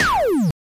snd_hitcar.ogg